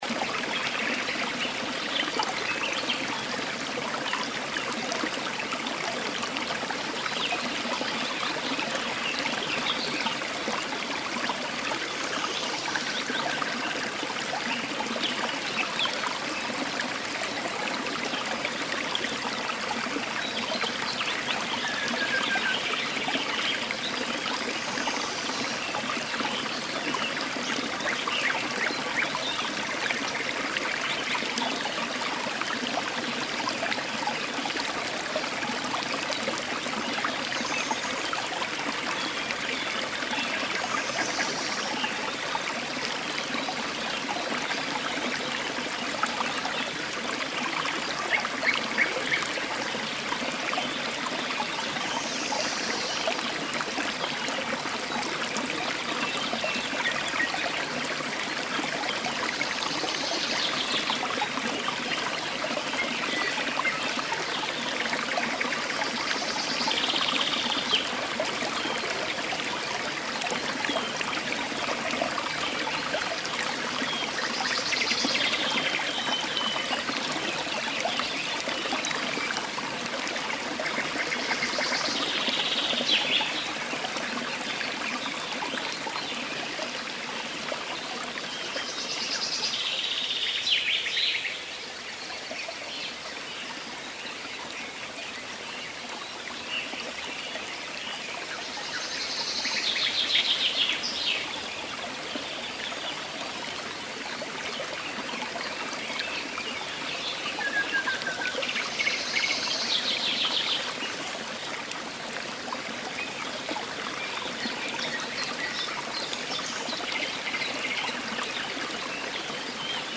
Звуки ручья